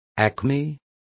Also find out how apogeos is pronounced correctly.